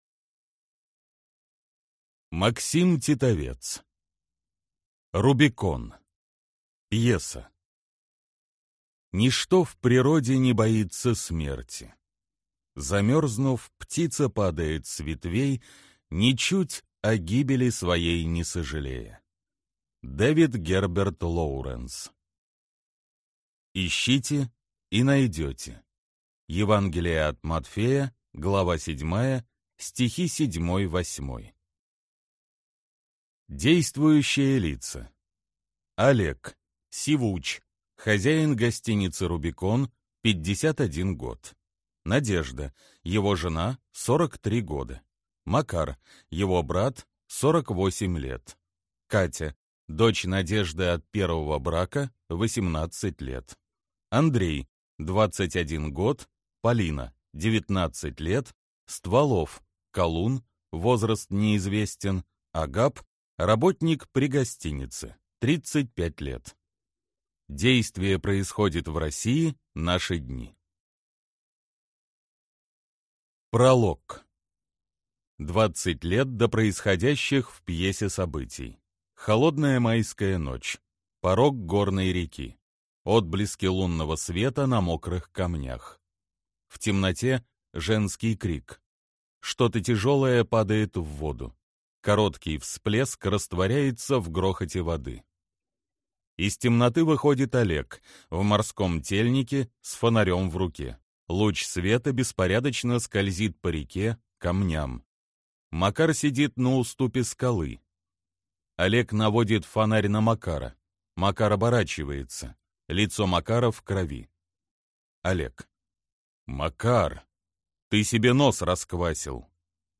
Аудиокнига Рубикон. Пьеса | Библиотека аудиокниг